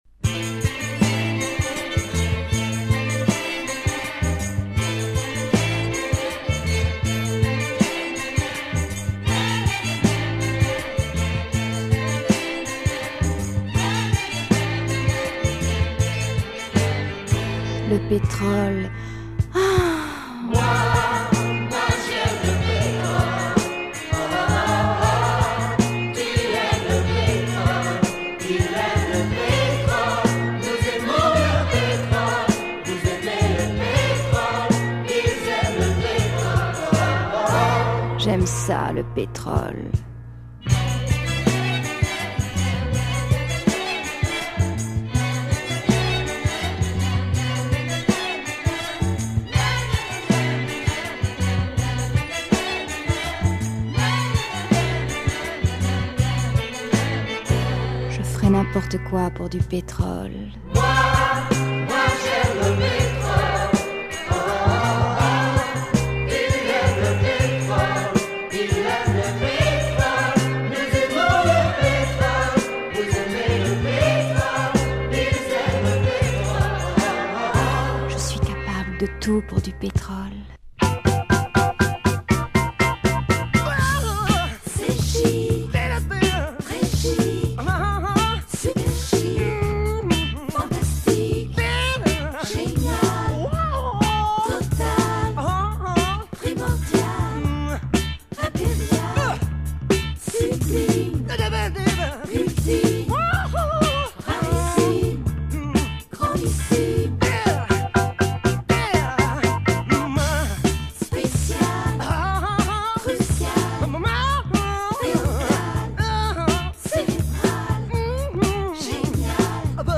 A nice and cult French soundtrack
Includes the ultra pop groove
with female vocals and sensual whispering
downtempo pop
sung by a female chorus